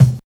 100 KICK 5.wav